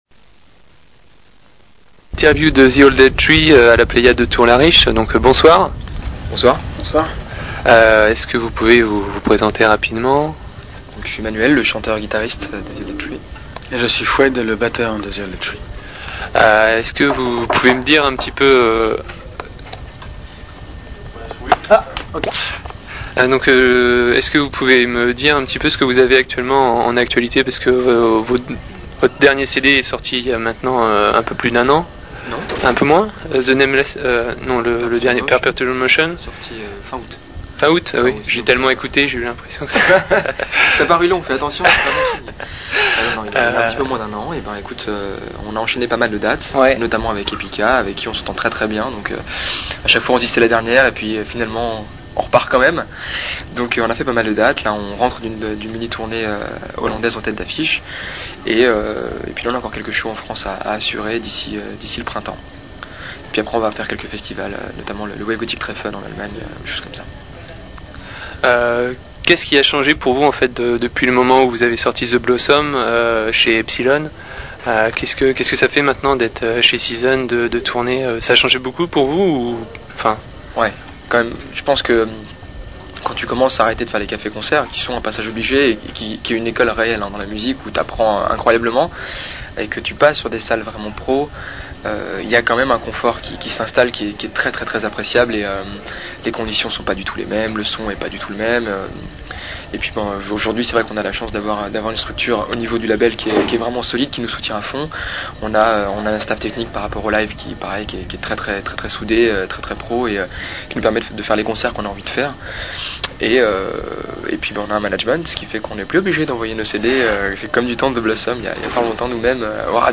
interview du groupe The Old Dead Tree enregistrée le 14.04.2006